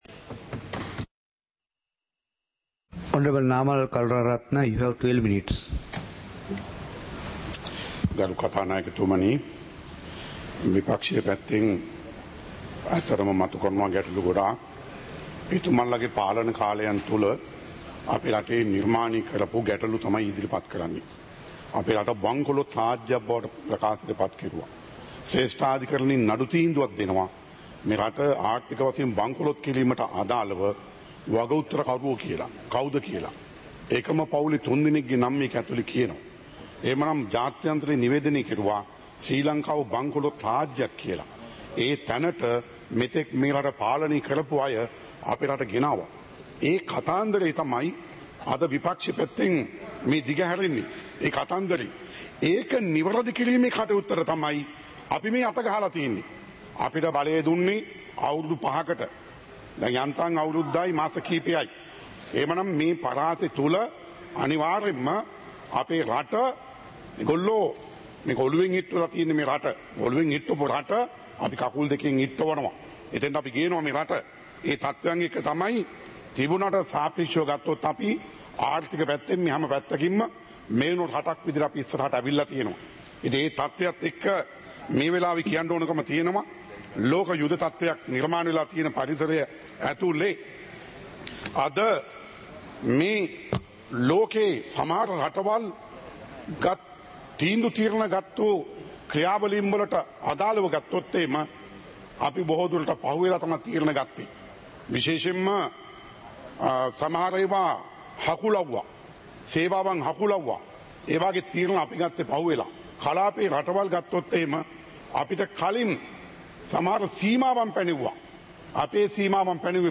சபை நடவடிக்கைமுறை (2026-03-20)